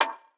[Snr] Baseball.wav